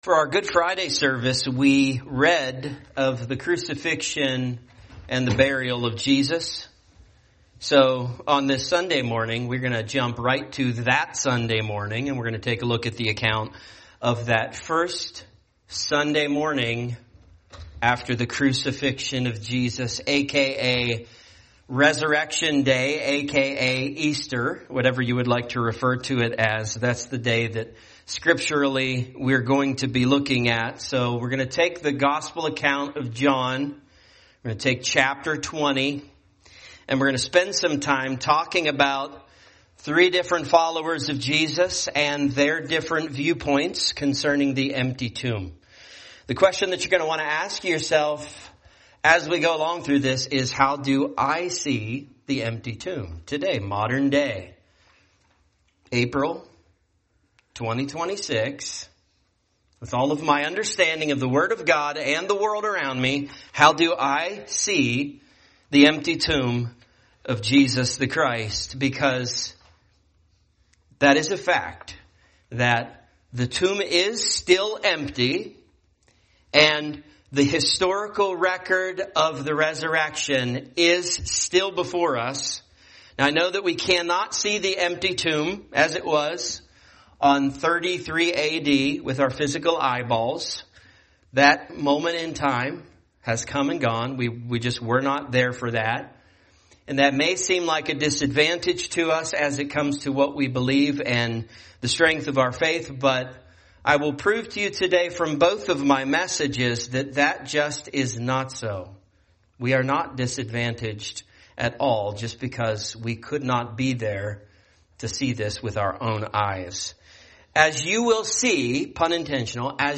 Message
From Topics: "Sunrise Service"